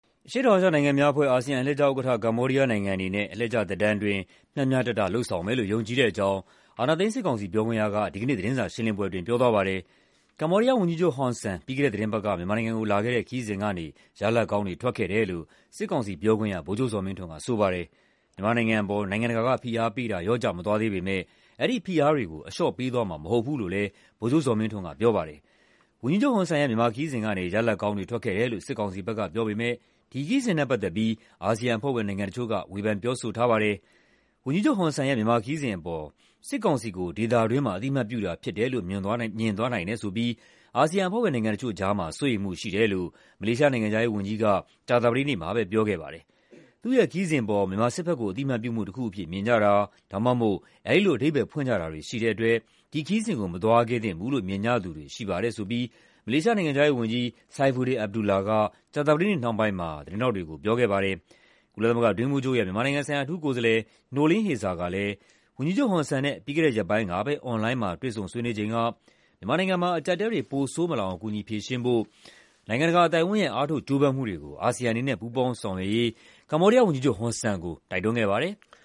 အရှေ့တောင်အာရှနိုင်ငံများအဖွဲ့ (ASEAN) အလှည့်ကျဥက္ကဋ္ဌ ကမ္ဘောဒီးယားနိုင်ငံအနေနဲ့ အလှည့်ကျသက်တမ်းအတွင်း မျှမျှတတလုပ်ဆောင်မယ်လို့ ယုံကြည်တဲ့အကြောင်း အာဏာသိမ်းစစ်ကောင်စီ ပြောခွင့်ရက ဒီကနေ့ သတင်းစာရှင်းလင်းပွဲ အတွင်း ပြောသွားပါတယ်။